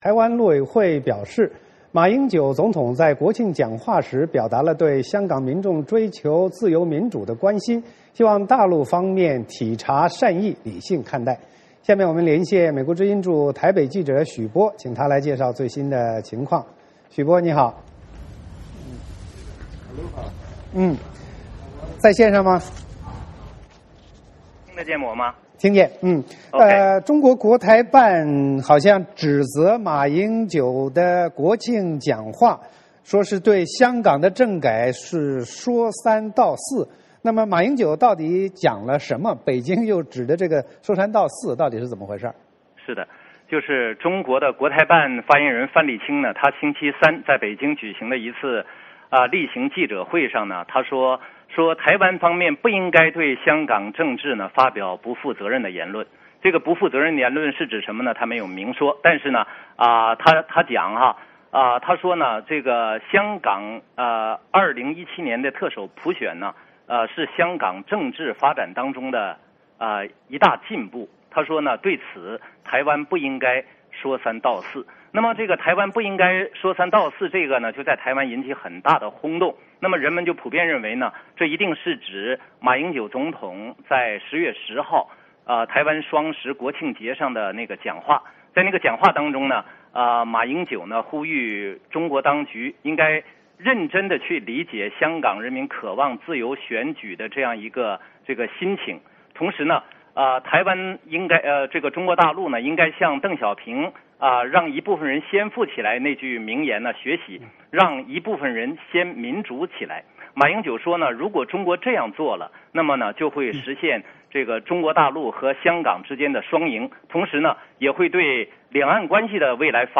VOA连线：台湾：大陆应理性看马英九的香港表态